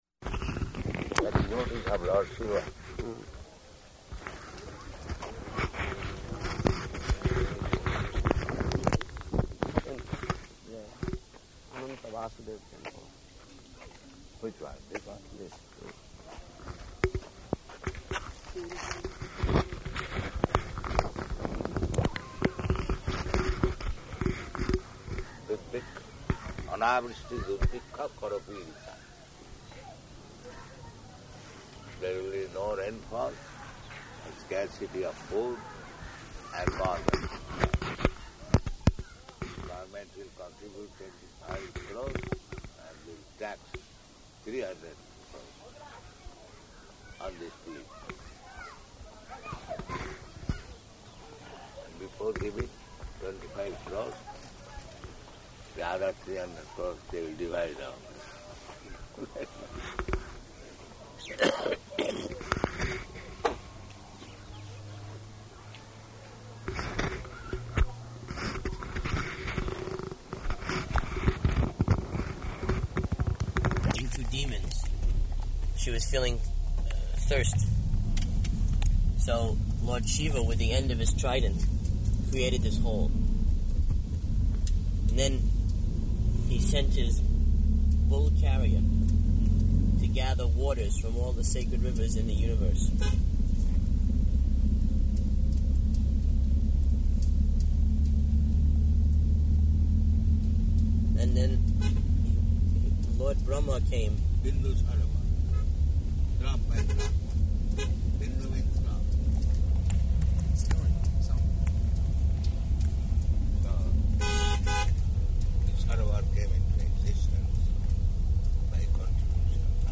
Morning Walk [partially recorded]
Type: Walk
Location: Bhubaneswar